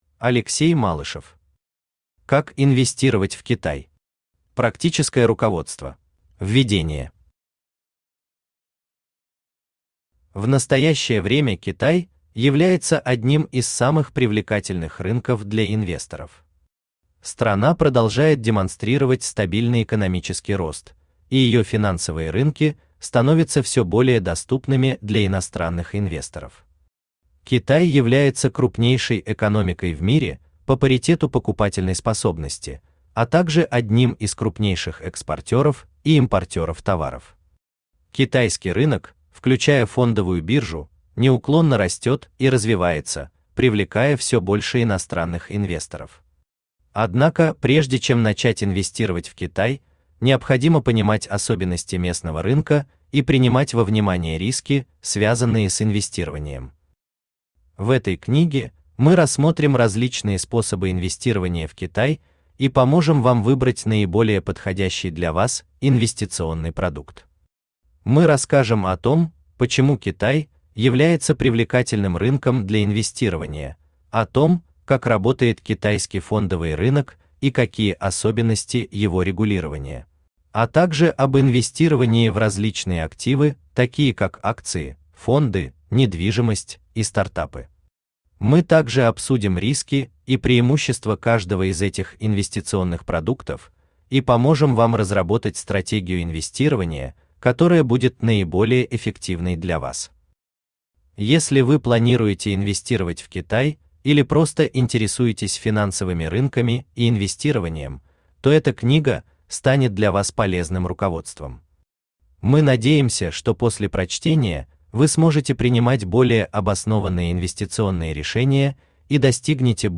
Аудиокнига Как инвестировать в Китай? Практическое руководство | Библиотека аудиокниг
Практическое руководство Автор Алексей Владимирович Малышев Читает аудиокнигу Авточтец ЛитРес.